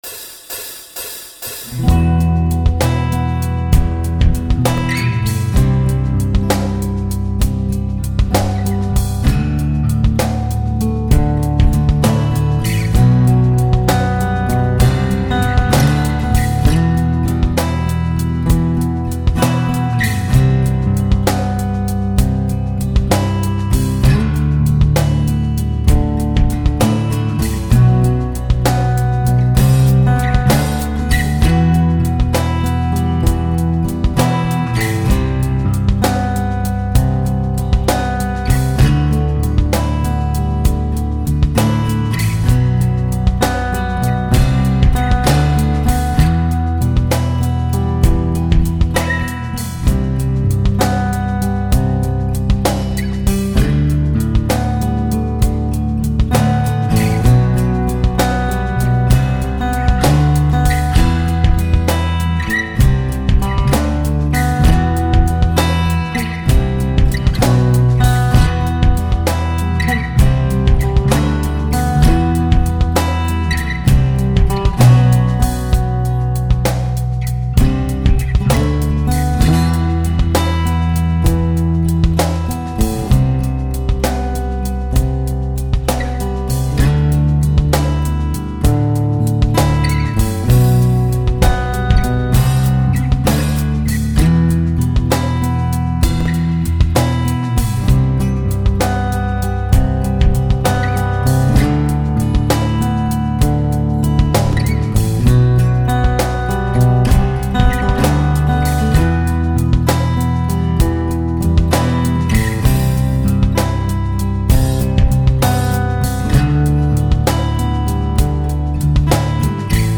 Der Backingtrack für unseren 50. aussenjam - Paws & Wings.
Ach ja, die Ibanez und der Squier gehen über einen Boss LS-2 in den Mac.
aussenjam50_backingtrack.mp3